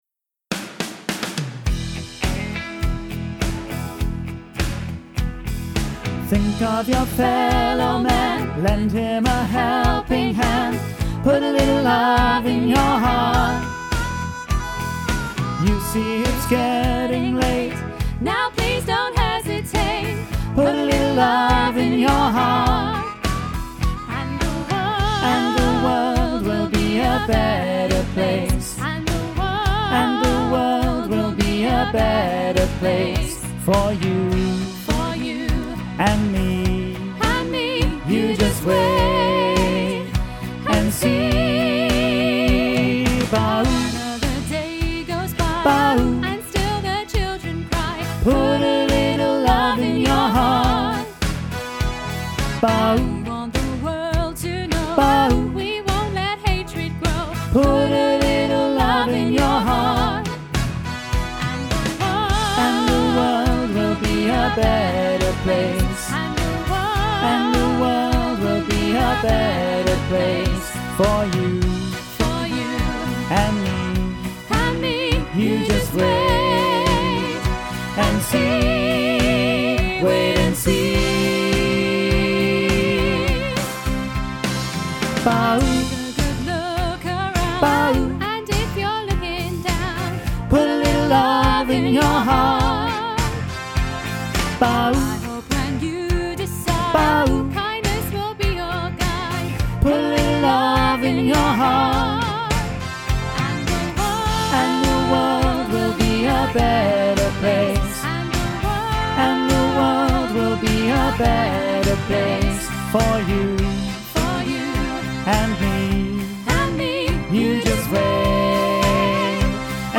Listen to bass track with soprano and alto accompaniment
put-a-little-love-bass-half-mix.mp3